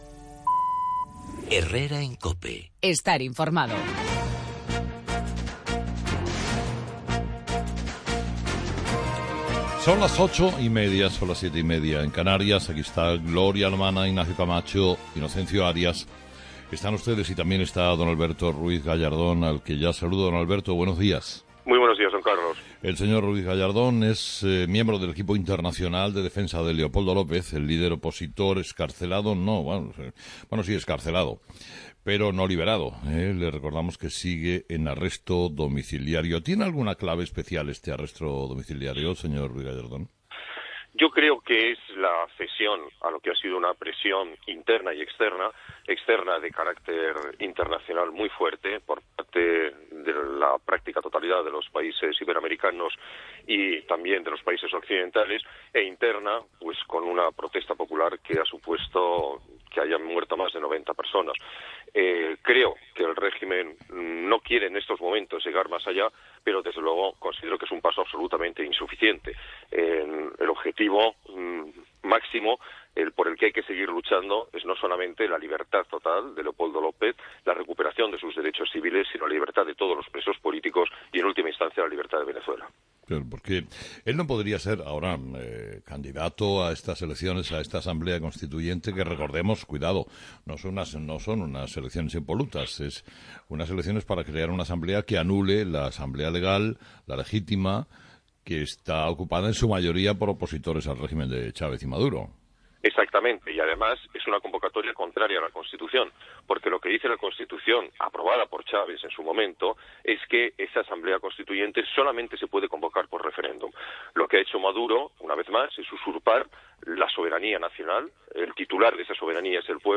Entrevista al abogado Ruíz Gallardón
Entrevistado: "Alberto Ruíz Gallardón"